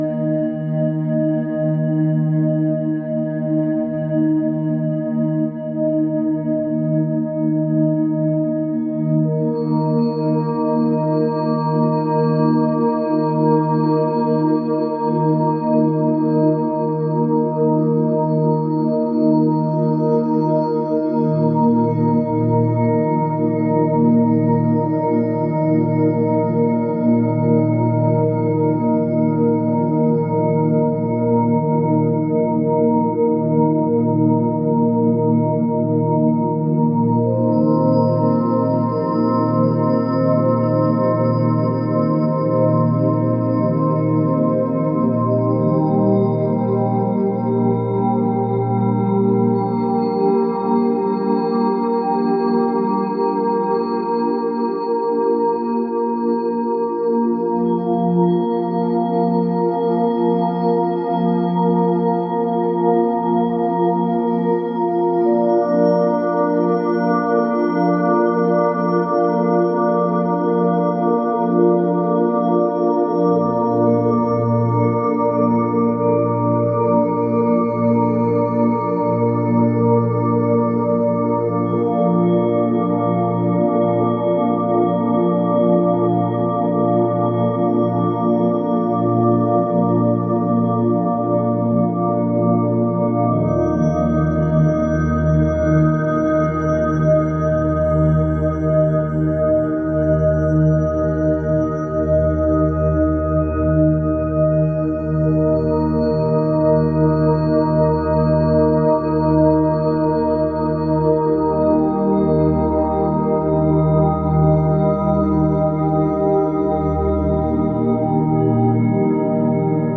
lagoon.ogg